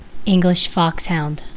Pronúncia